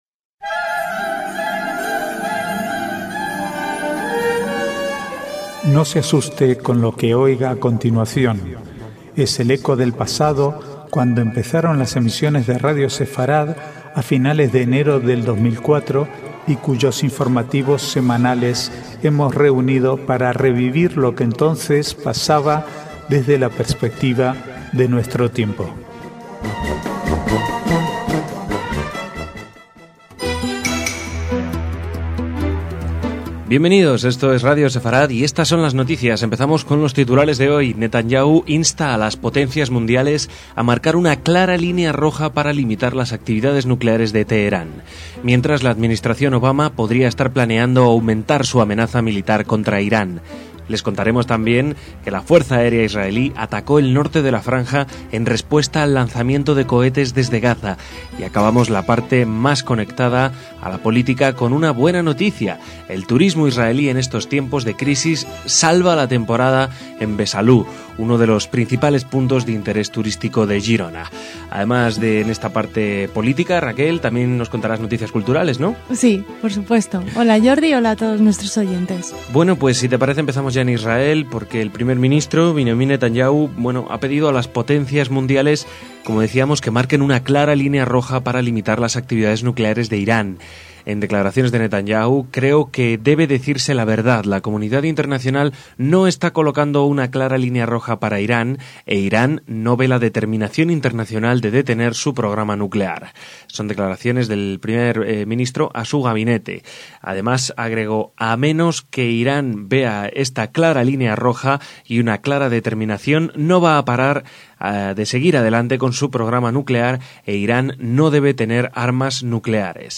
Archivo de noticias del 4 al 7/9/2012